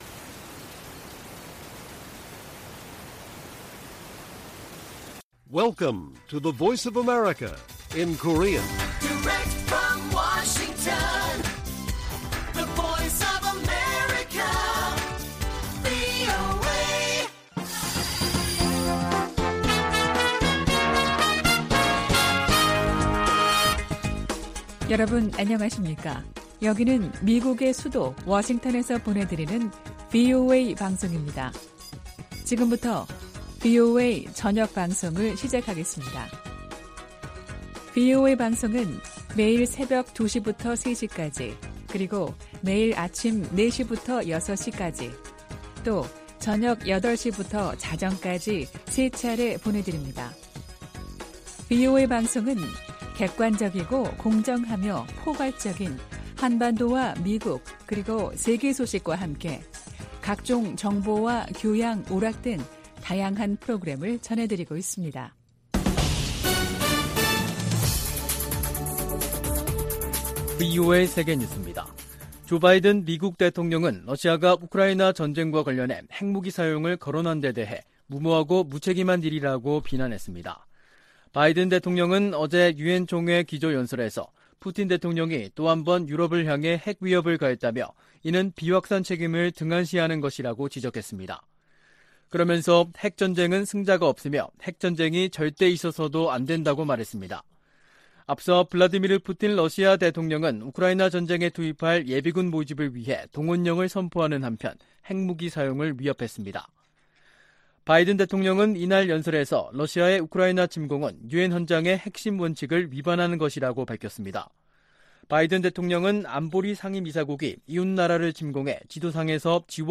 VOA 한국어 간판 뉴스 프로그램 '뉴스 투데이', 2022년 9월 22일 1부 방송입니다. 조 바이든 대통령과 윤석열 한국 대통령이 유엔총회가 열리고 있는 뉴욕에서 만나 북한 정권의 위협 대응에 협력을 재확인했습니다. 한일 정상도 뉴욕에서 대북 협력을 약속하고, 고위급 외교 채널을 통해 양국 관계 개선 노력을 가속화하기로 합의했습니다. 바이든 대통령은 유엔총회 연설에서 북한의 지속적인 유엔 제재 위반 문제를 지적했습니다.